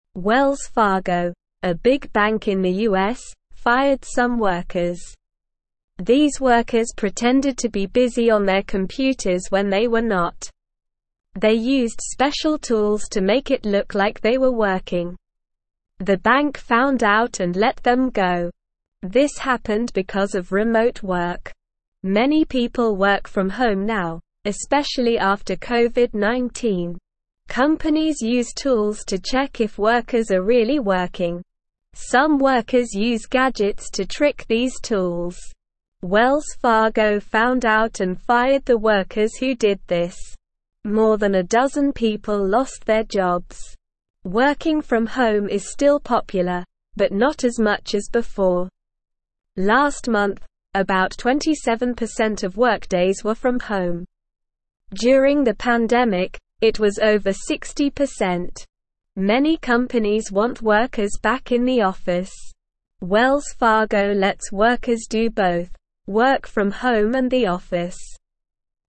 Slow
English-Newsroom-Beginner-SLOW-Reading-Wells-Fargo-Fires-Workers-for-Pretending-to-Work.mp3